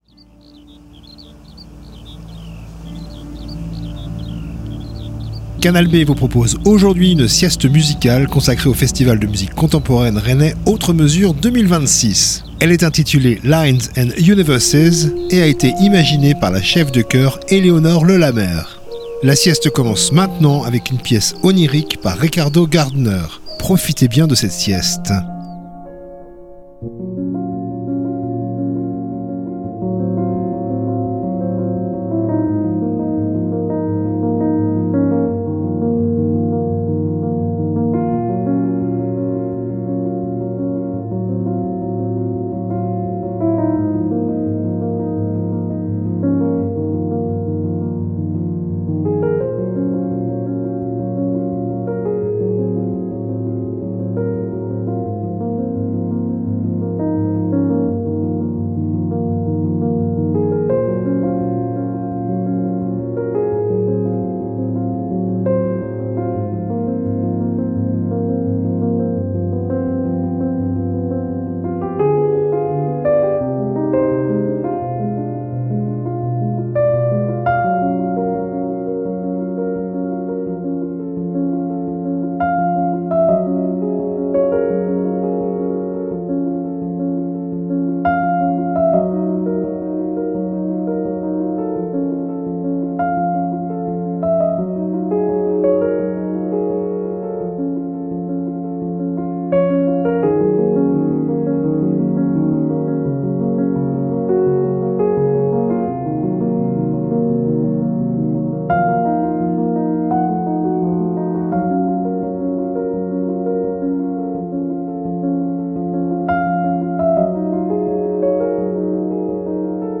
sieste musicale